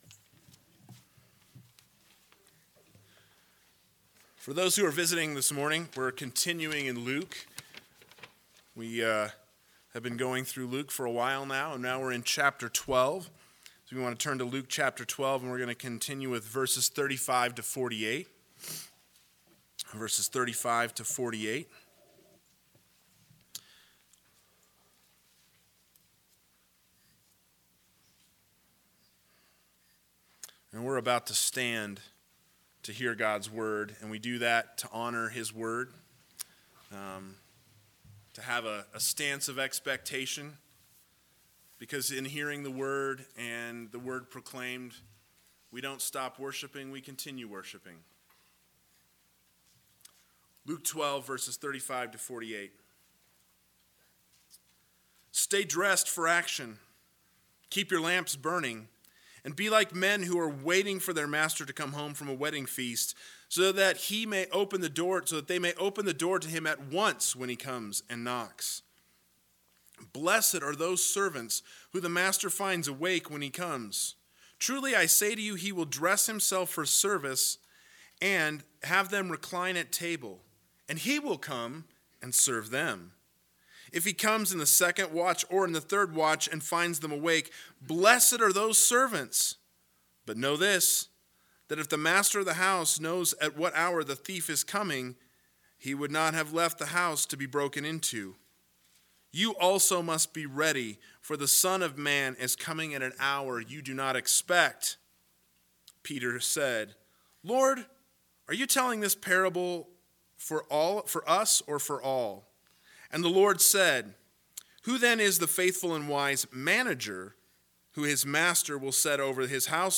AM Sermon – 09/27/2020 – Luke 12:35-48 – Ready or Not, Here I Come!